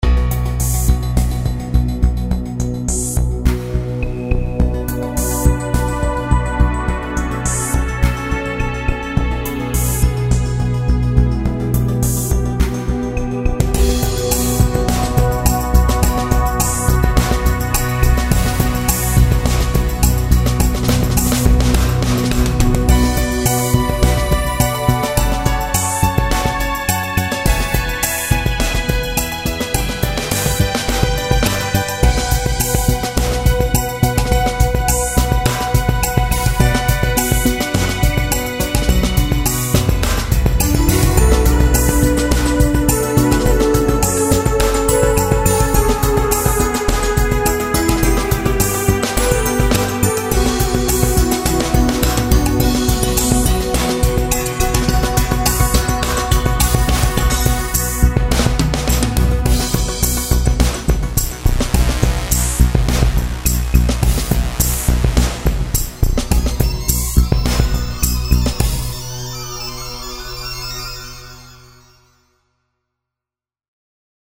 Multisample-based, fully-featured synthesizer engine